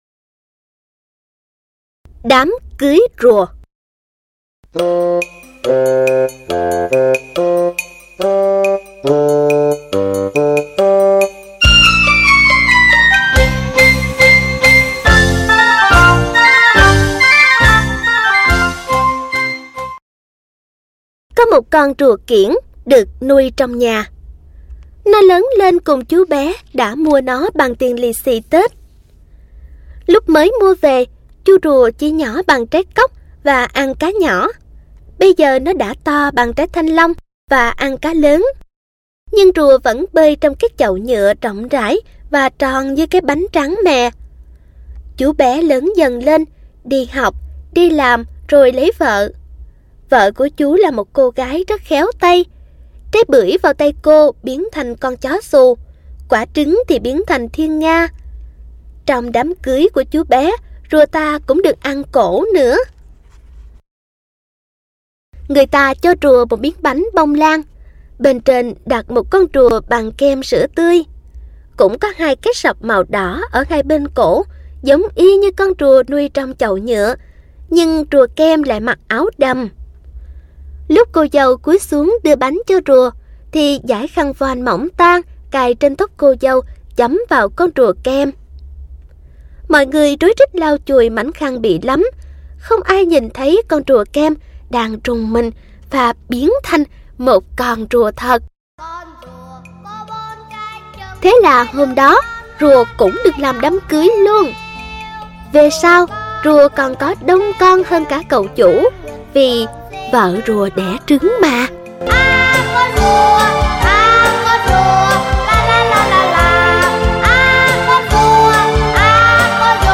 Sách nói | Xóm Đồ Chơi P28